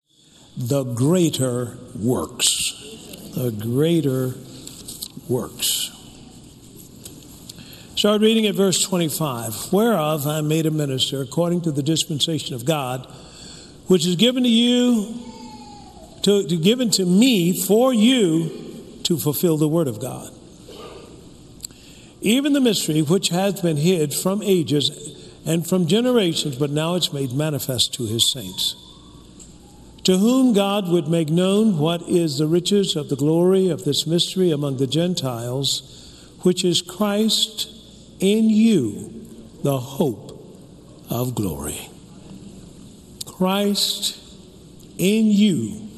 (4 Teachings) Do you have faith in the Anointing of God on your life?